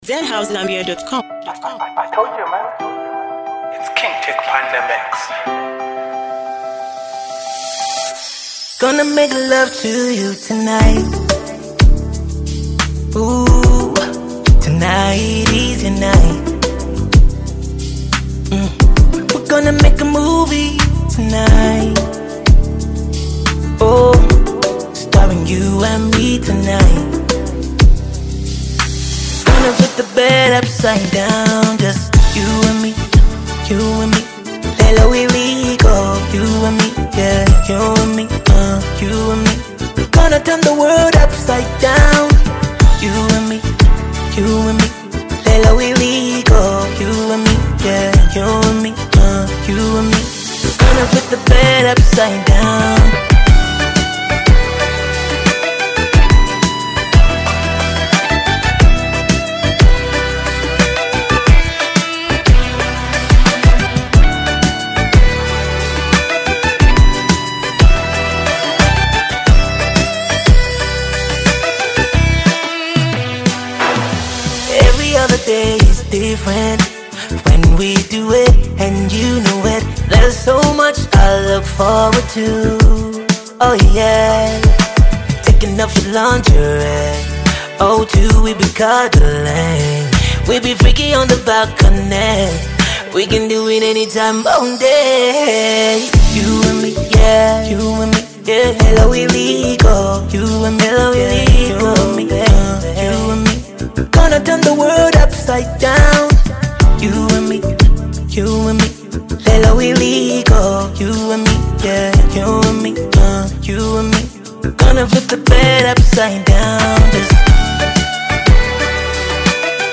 soulful vocals
an alluring beat